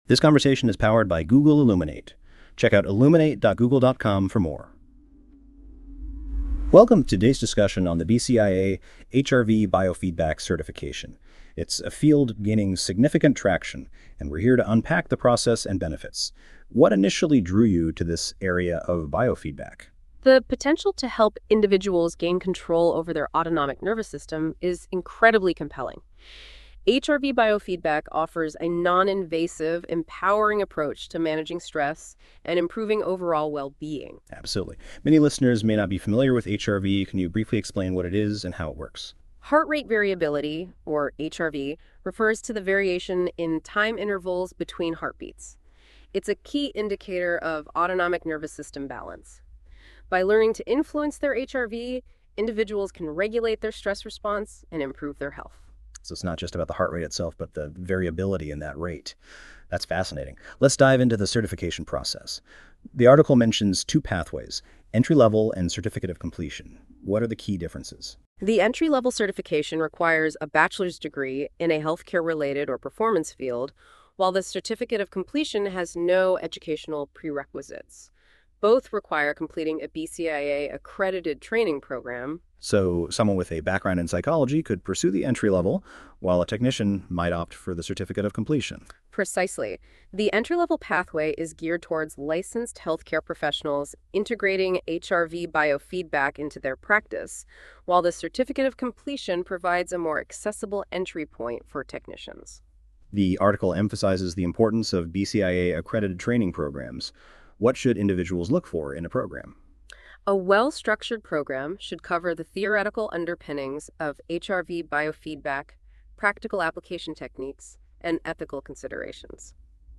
Click on the podcast icon for a Google Illuminate discussion.